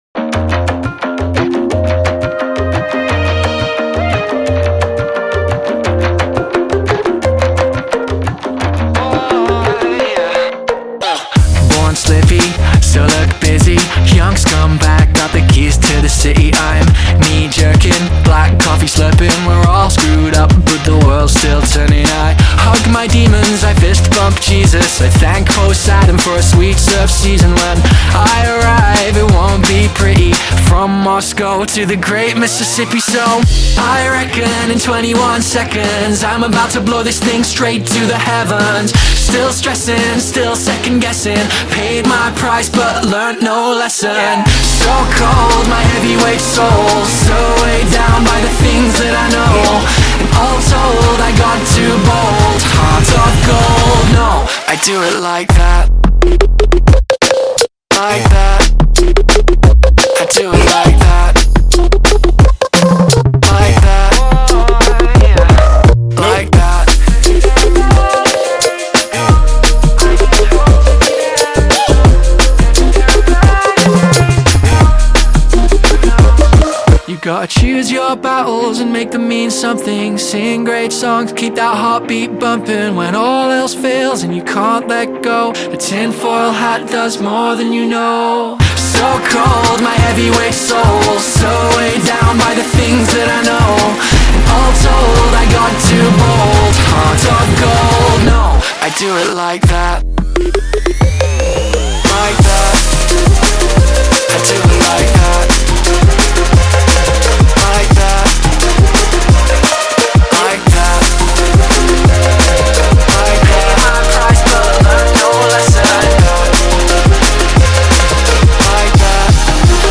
BPM174
MP3 QualityMusic Cut